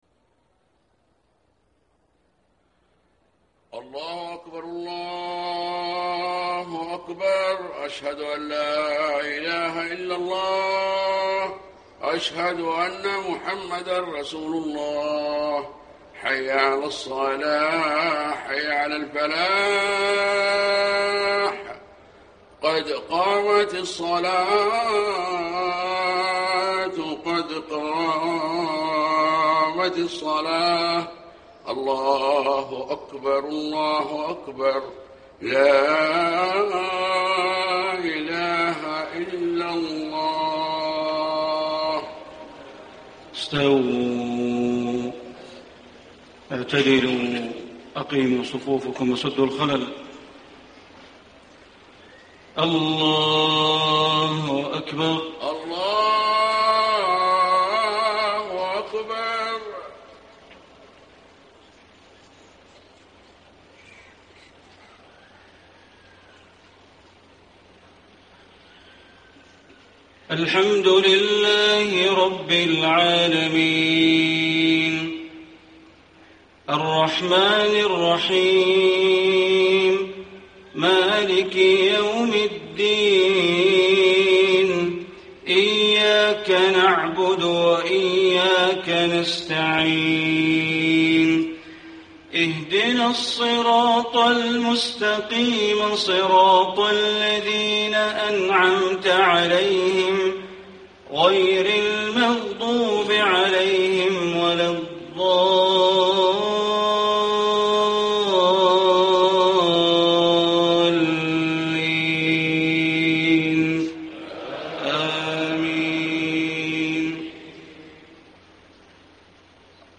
صلاة العشاء 4 - 7 - 1435هـ آخر سورتي الرعد و غافر > 1435 🕋 > الفروض - تلاوات الحرمين